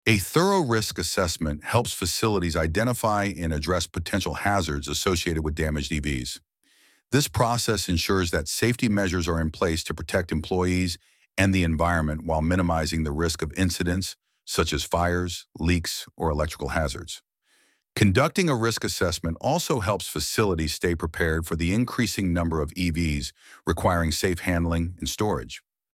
ElevenLabs_Topic_3.1.2.mp3